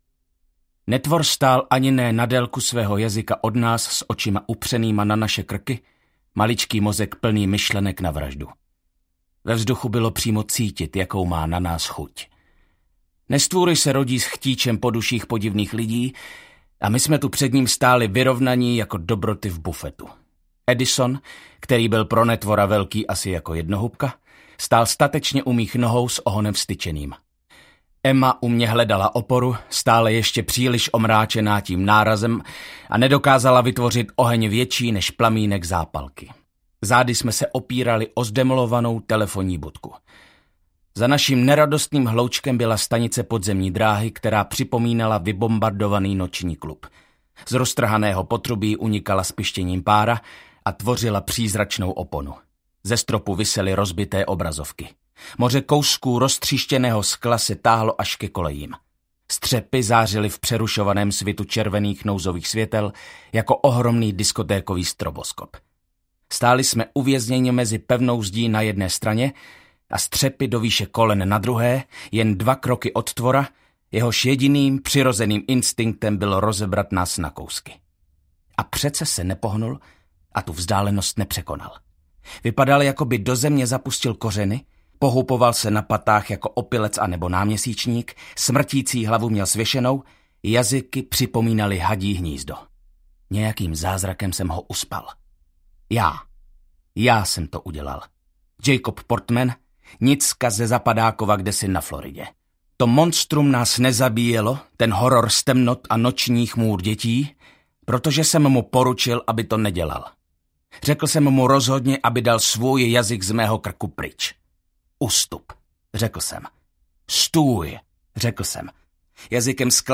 Ukázka z knihy
sirotcinec-slecny-peregrinove-knihovna-dusi-audiokniha